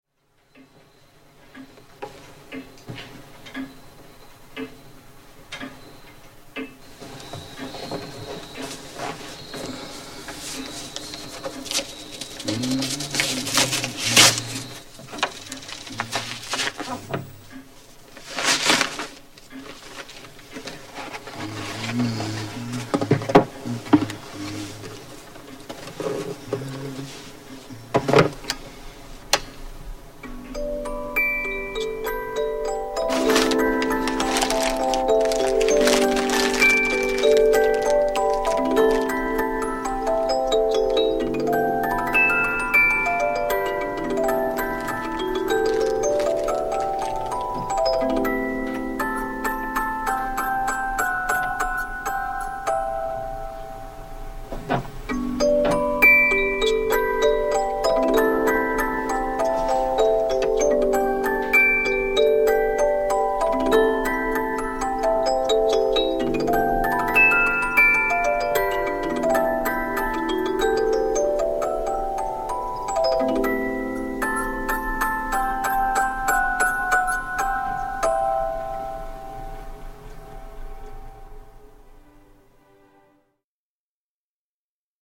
Cajita Musical - Claro de Luna
Cajita Musical fabricada en 1940 apróximadamente con el tema Claro de Luna de Claude Debussy.